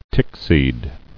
[tick·seed]